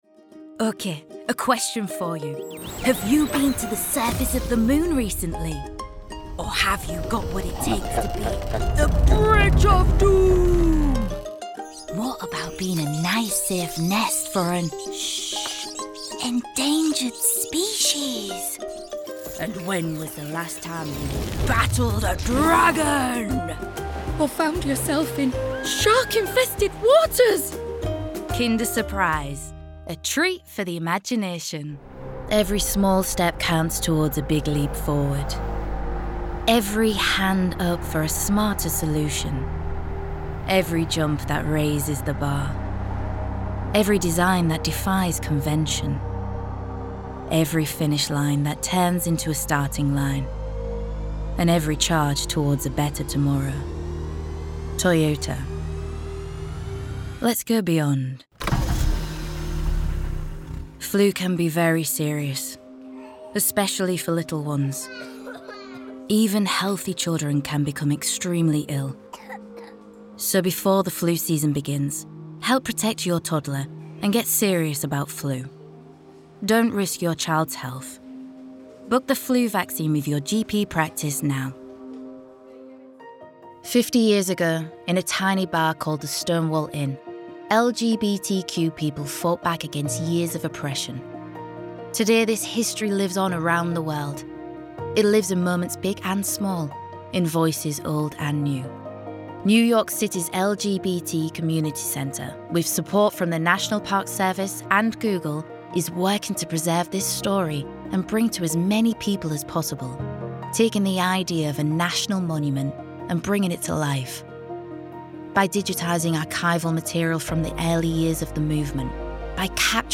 Commercial Showreel
Female
Hull - Yorkshire
Northern
Friendly
Playful